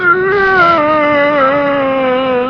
zombie_dies.ogg